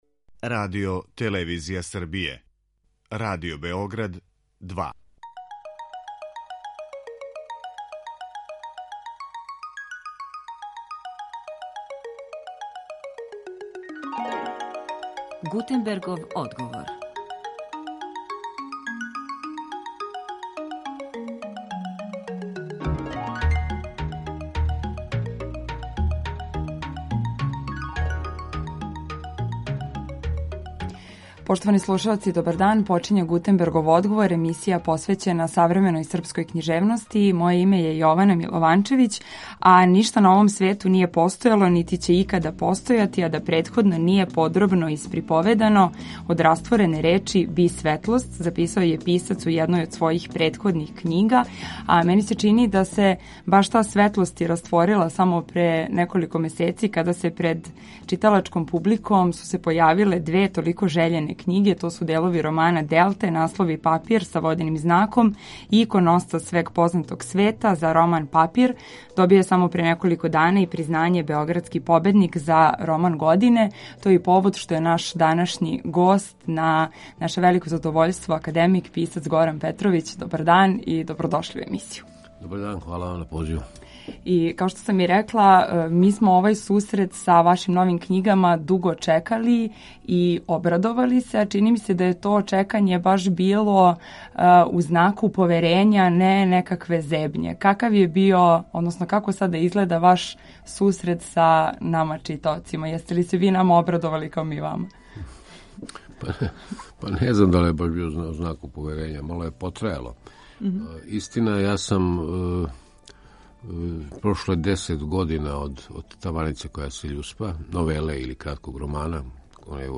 Гост емисије Гутенбергов одговор је академик Горан Петровић.